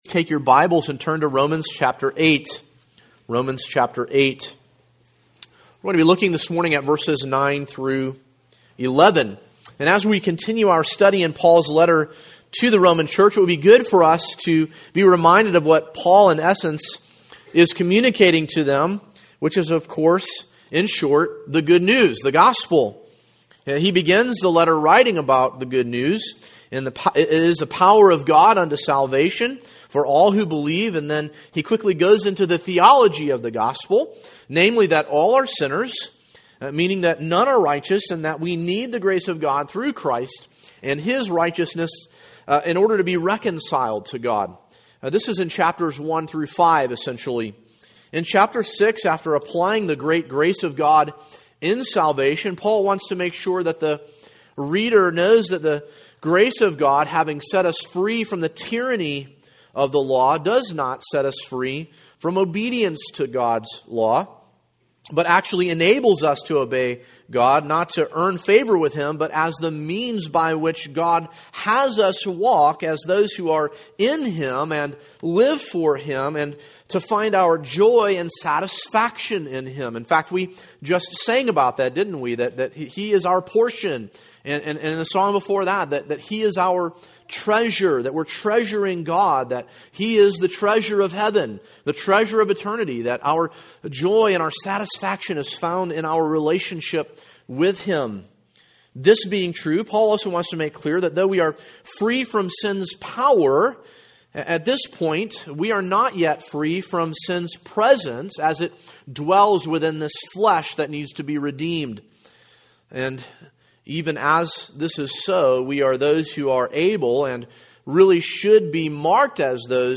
An exposition